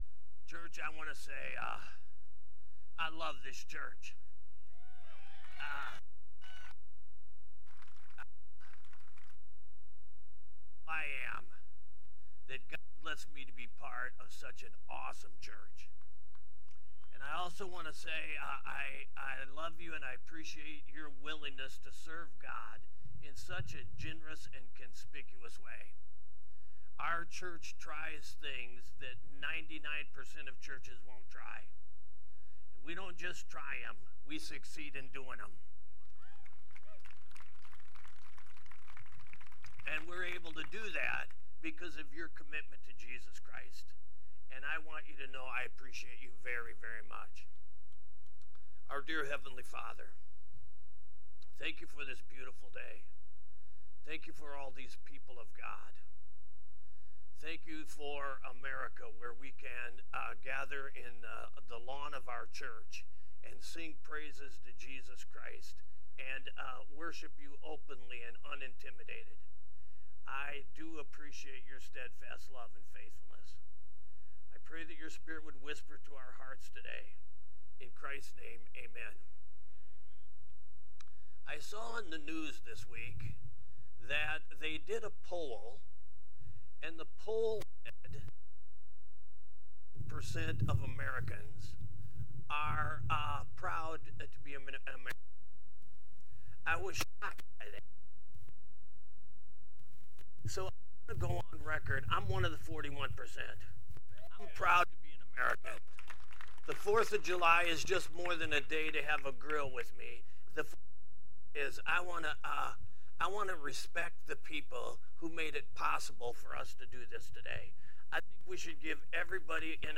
All Campus Service